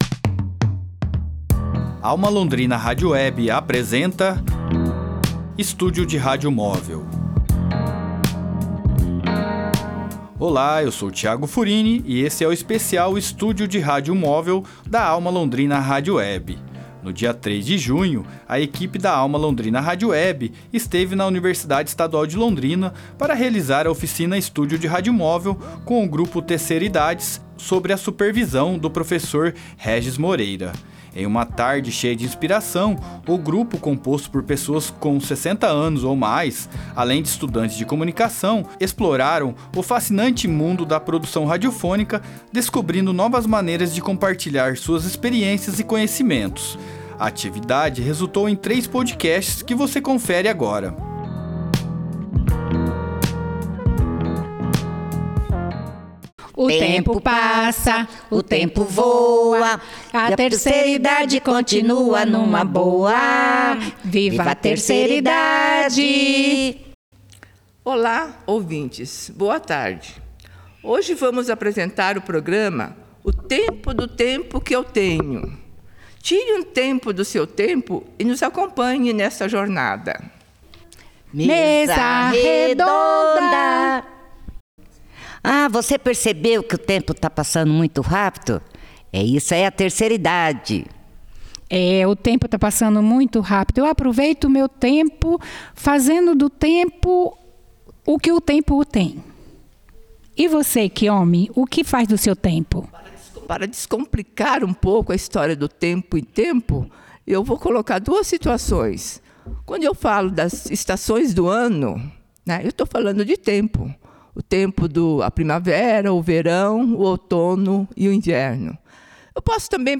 Em uma tarde cheia de inspiração, o grupo, composto por pessoas com 60 anos ou mais, além de estudantes de Comunicação Social, exploraram o fascinante mundo da produção radiofônica, descobrindo novas maneiras de compartilhar suas experiências e conhecimentos.